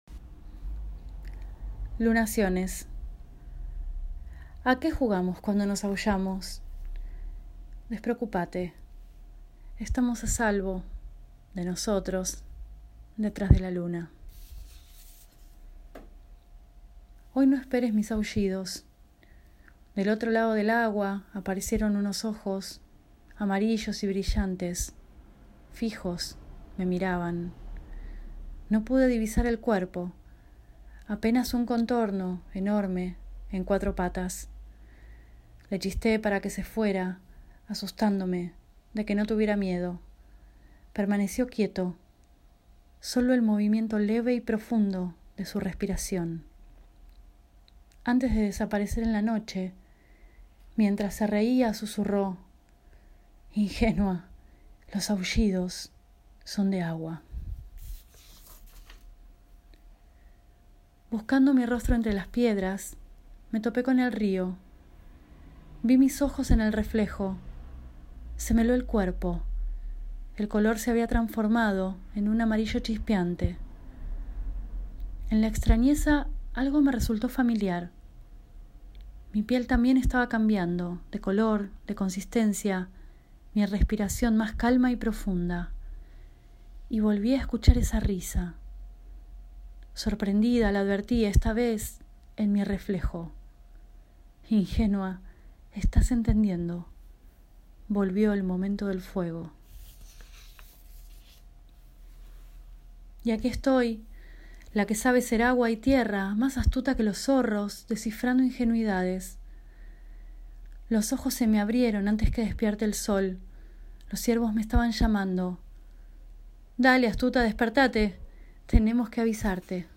leídos por la autora